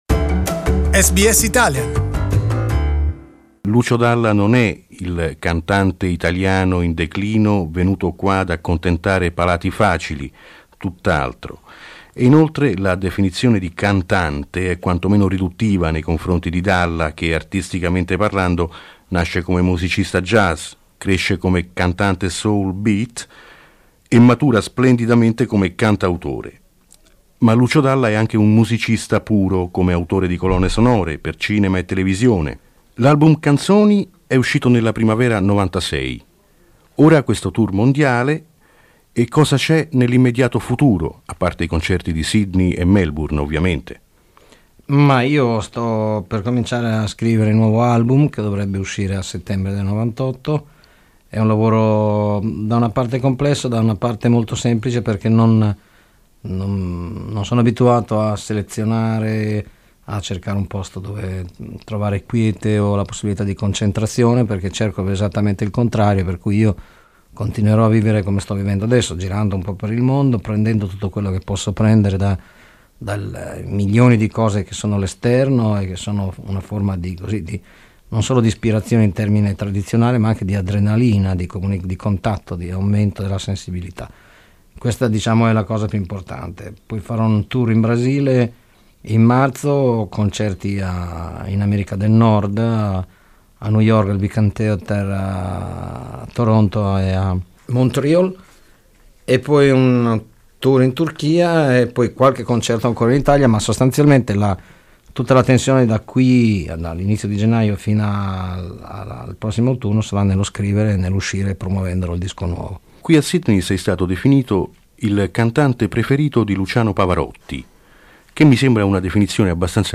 Nel 1996, dopo l'uscita dell'album 'Canzoni', il grande artista bolognese si esibì in concerto a Sydney e Melbourne, e concesse una lunga intervista a Radio SBS.